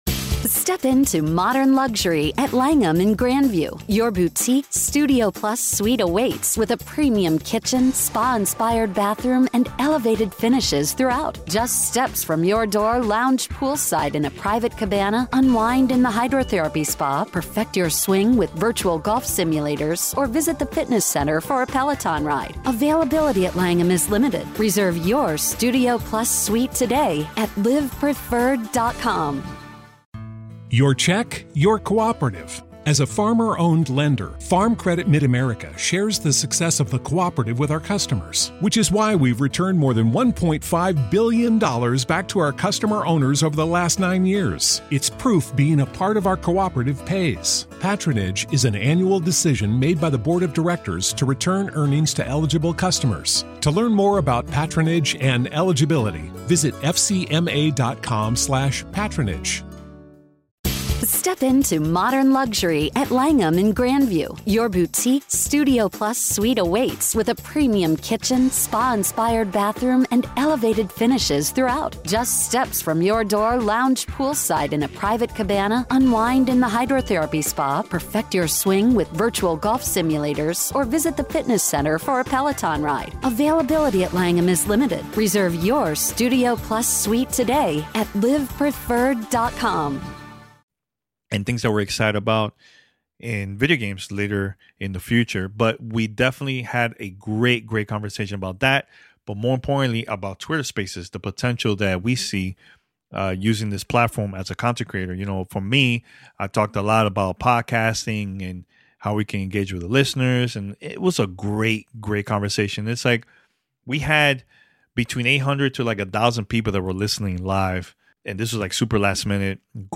The team at Twitter Spaces and Twitter Gaming invited us to participate a live panel session about our experience using Twitter Spaces, tips as host and the gaming community. We also spoke about how we are using twitter for gaming and how you stay close with our community.
Roundtable discussion